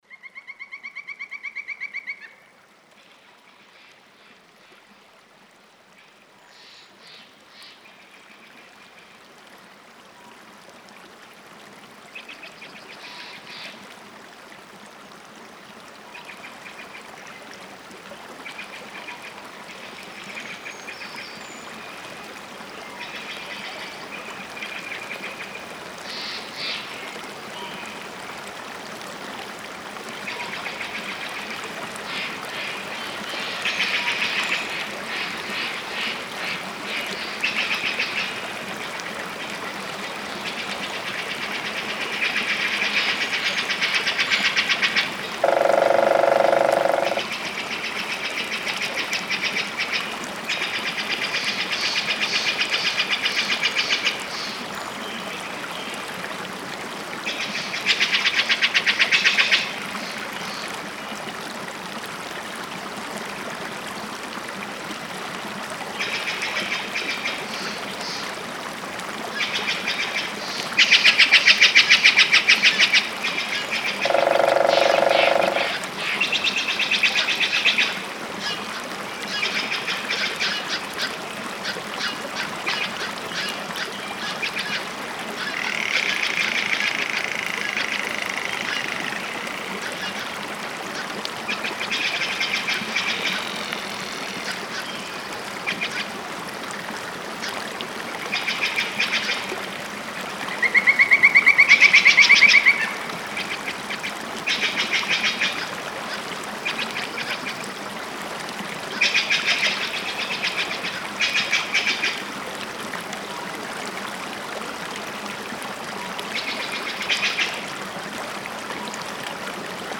3D spatial surround sound "Forest Stream"
3D Spatial Sounds